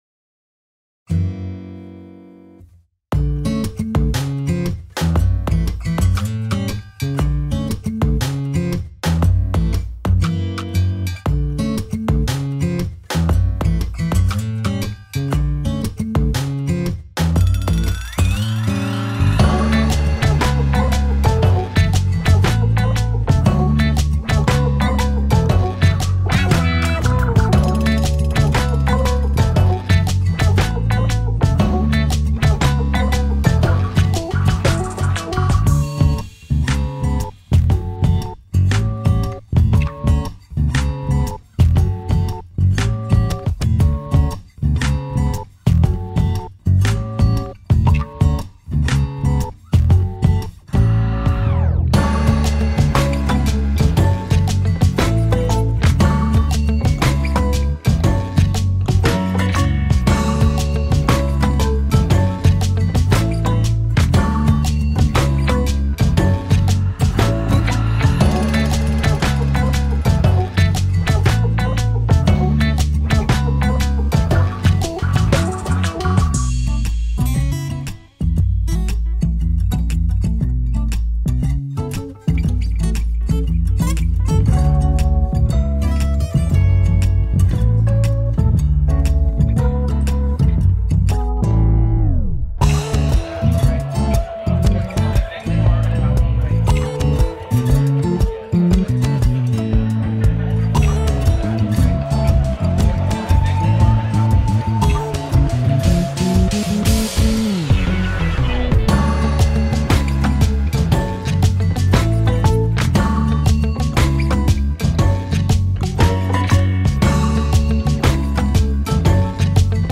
寄り酔い和ぬか-Instrumental-.mp3